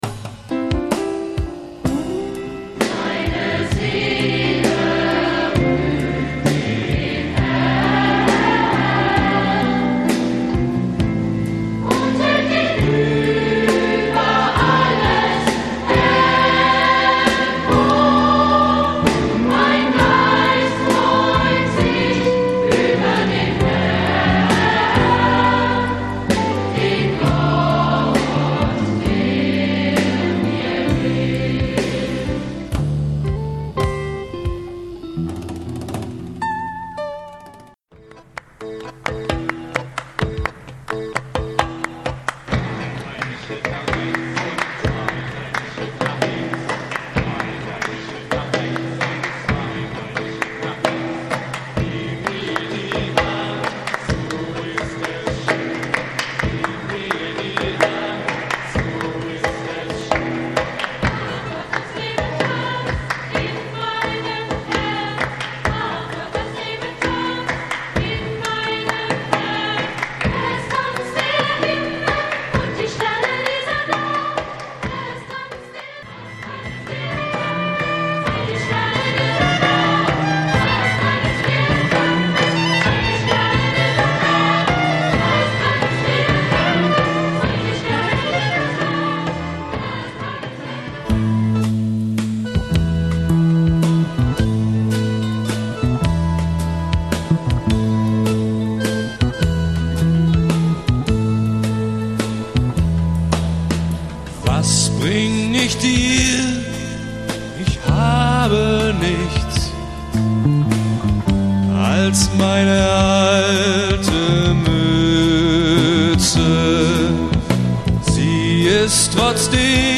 Besetzung: S.A.T.B., Soli, Band